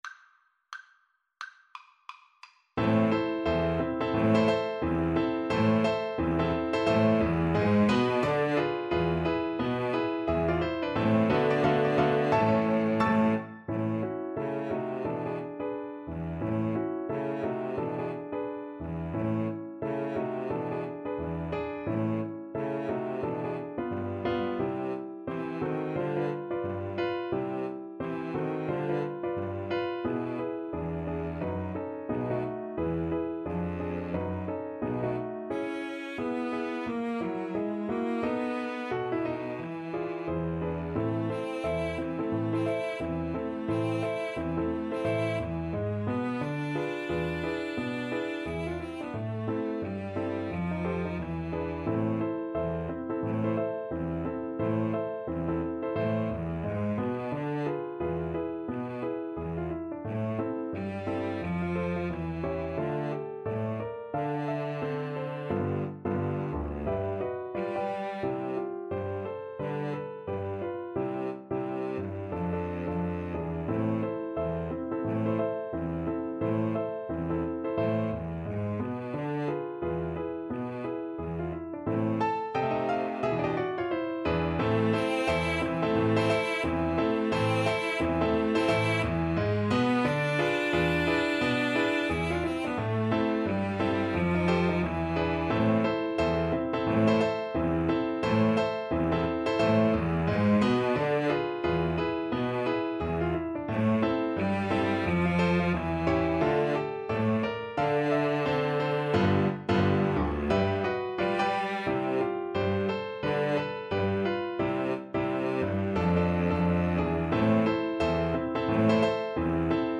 ~ = 176 Moderato
Jazz (View more Jazz Piano Trio Music)